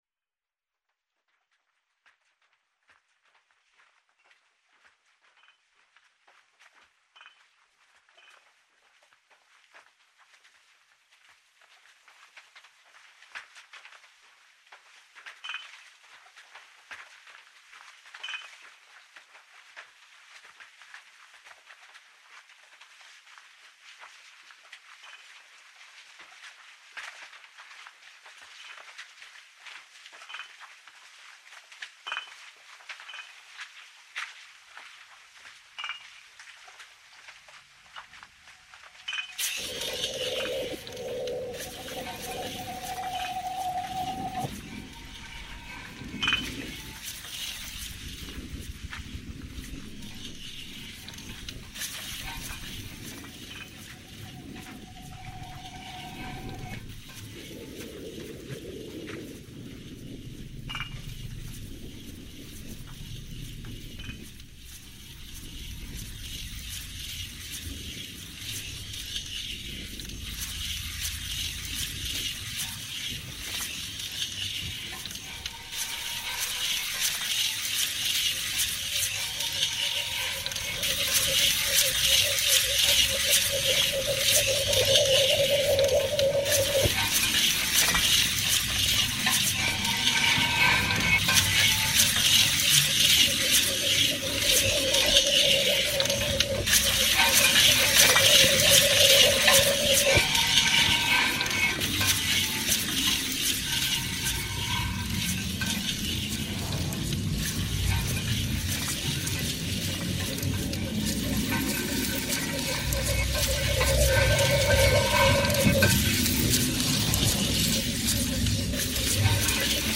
File under: Experimental